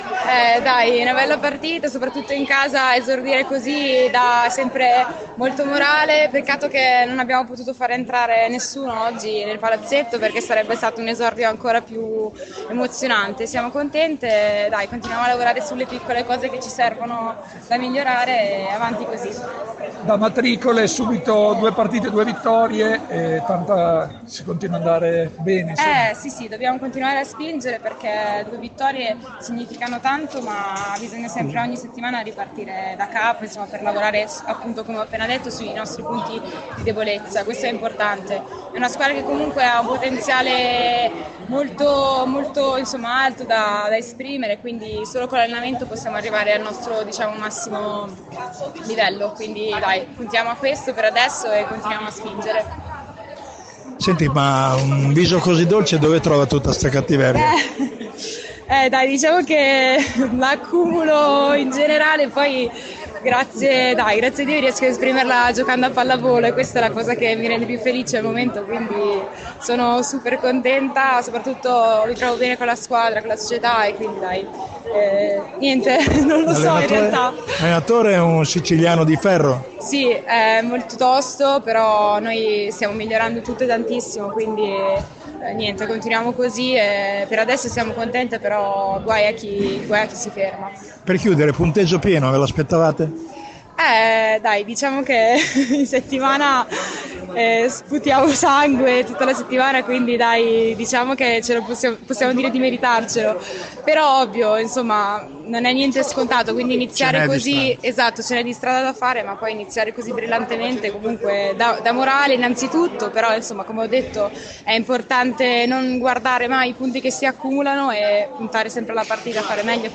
interviste-megabox-post-montecchio.mp3